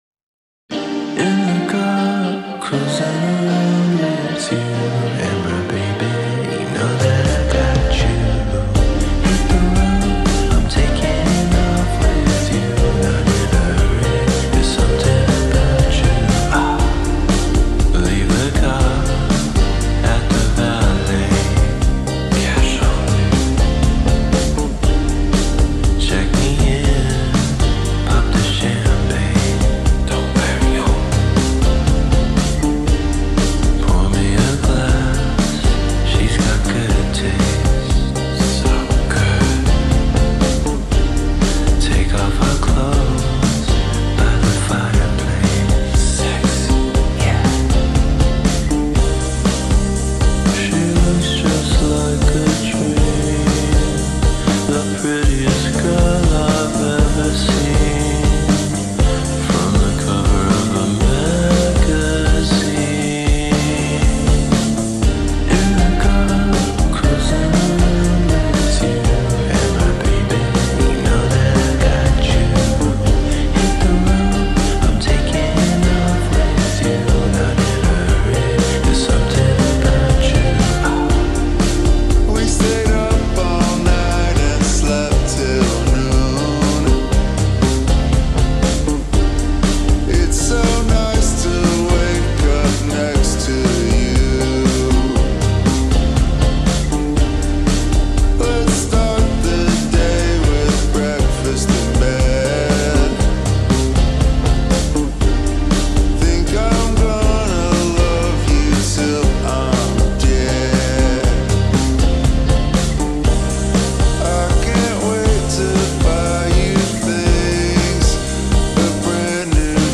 ریمیکس کاهش سرعت آهسته